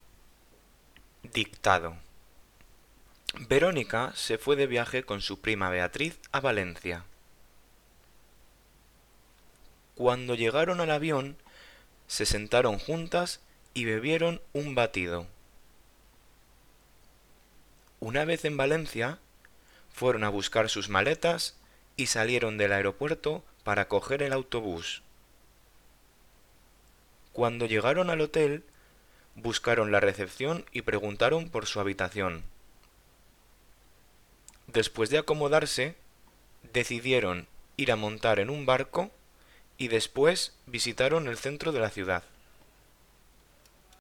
Dictado.mp3